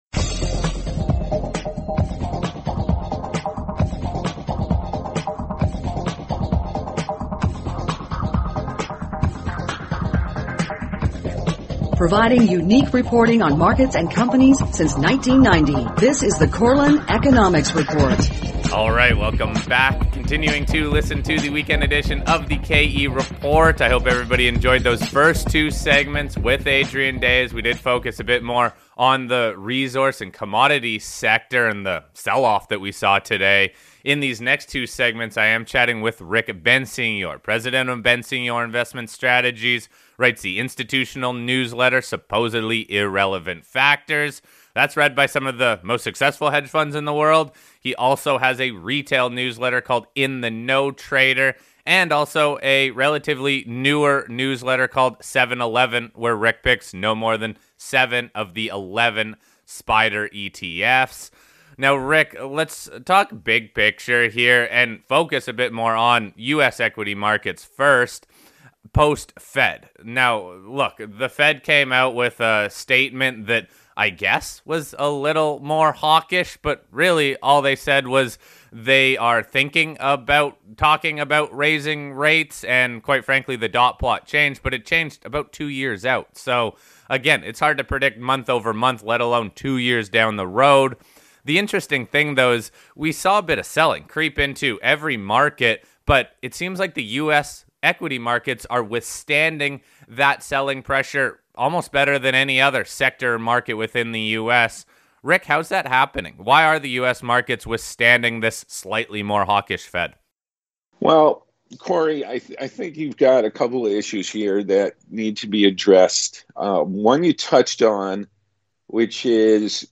This was a wild week in the markets dominated by the selloff in commodities, especially gold, on the back of the Fed statement and increased market volatility. I feature 2 well known guests this week in extended segments so we can dive into what happened and where the opportunities are.